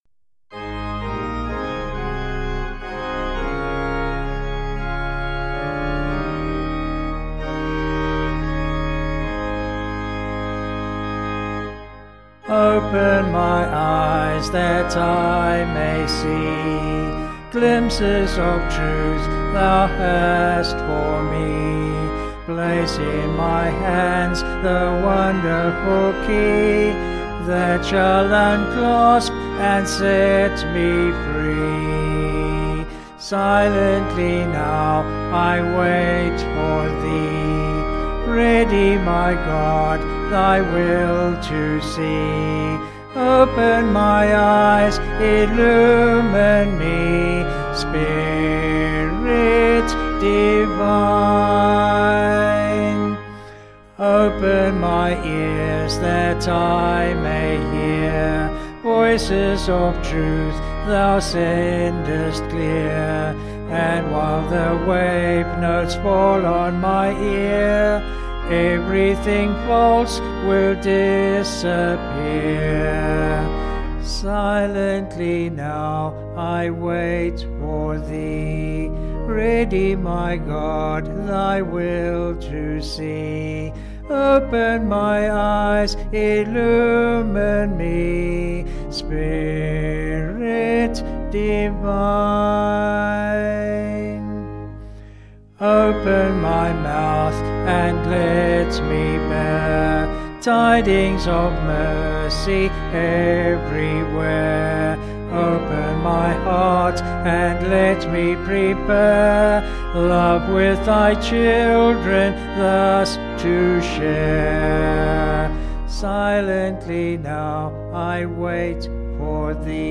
Vocals and Organ   264.3kb Sung Lyrics